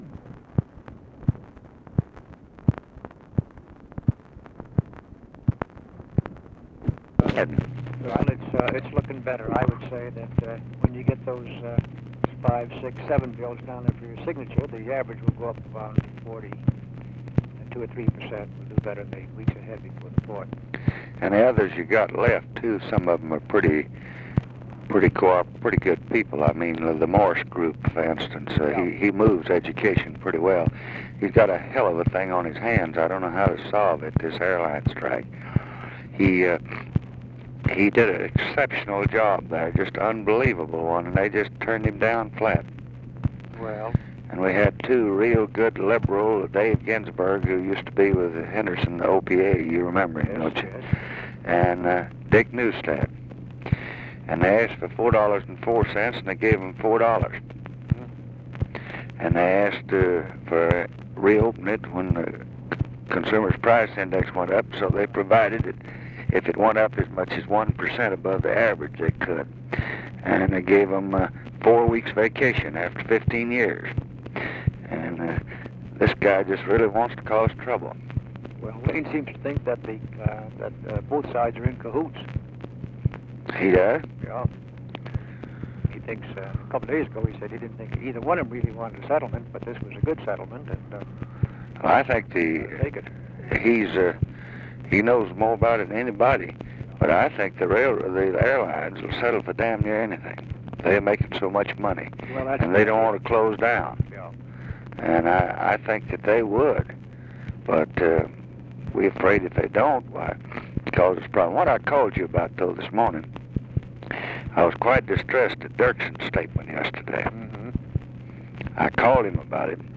Conversation with MIKE MANSFIELD, June 10, 1966
Secret White House Tapes